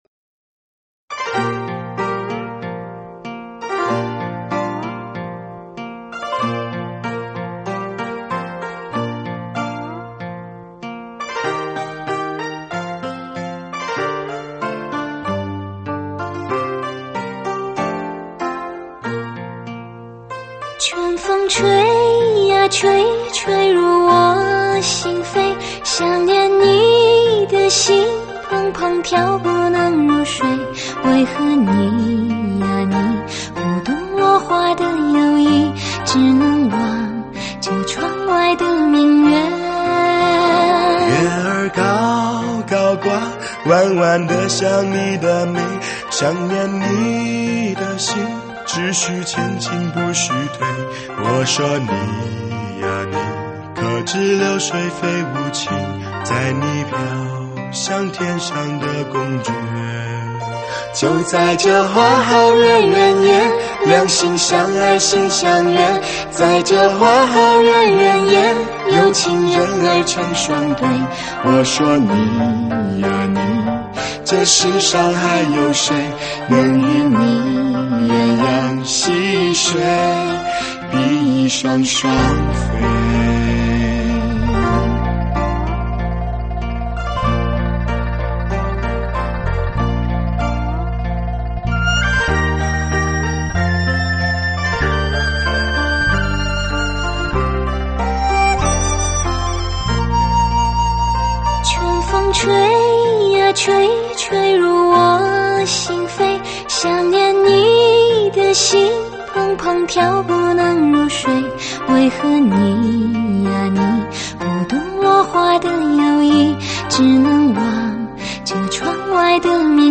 对唱